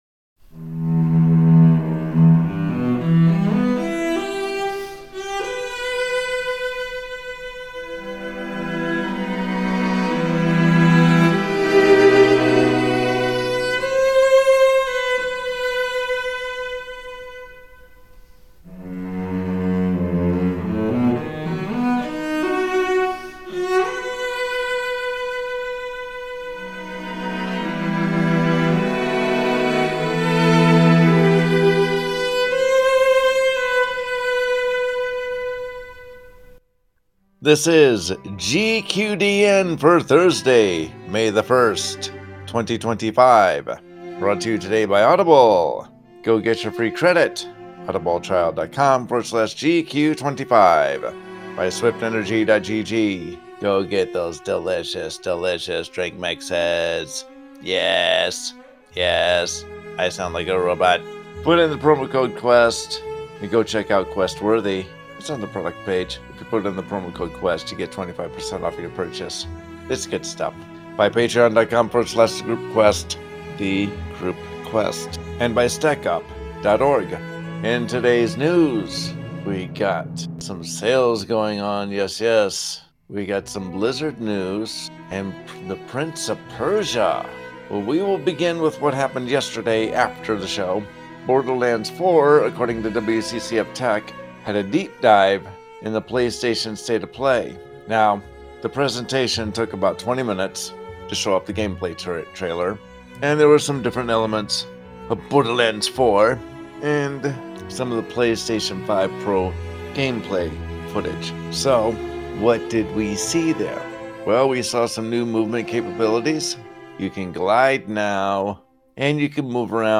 Group Quest branched off from the All Things Azeroth podcast as a way to gather a few members of the community together on a weekly basis to discuss the biggest theories, ideas, and news of the day.